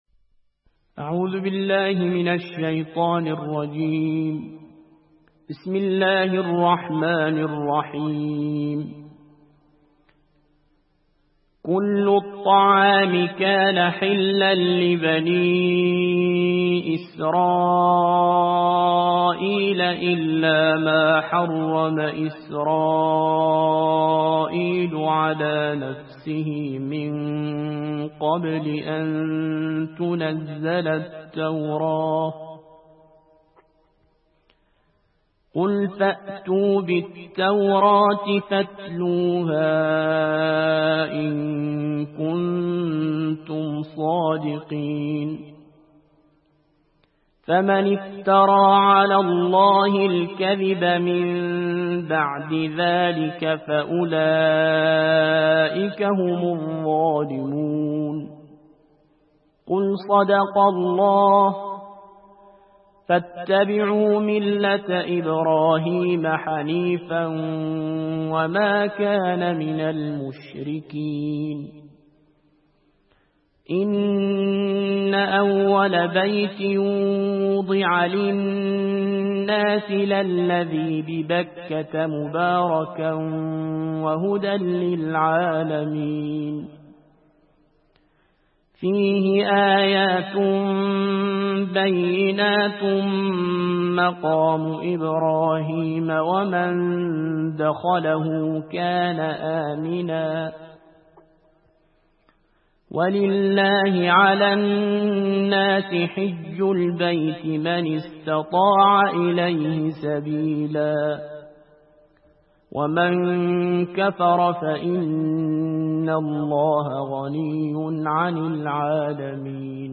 صوت/ ترتیل جزء چهارم قرآن